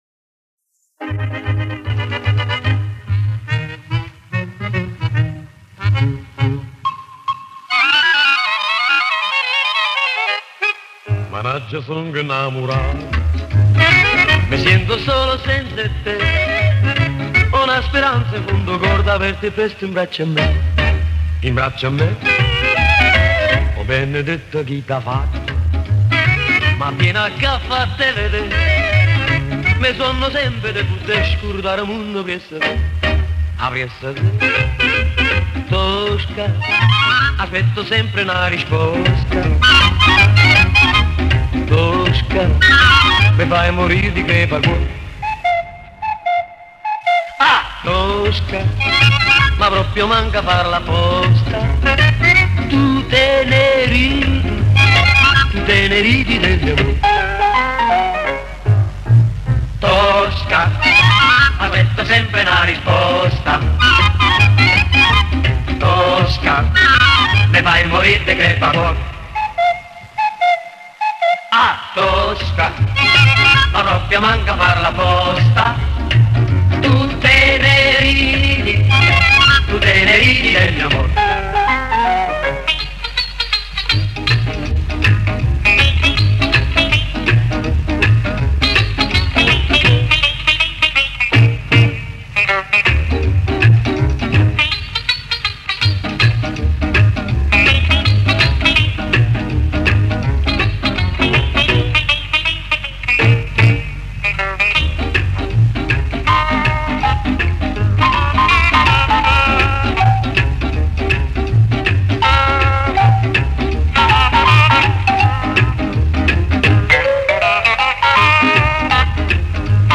Поет итальянец, даже наполетанец. Акцент явно их.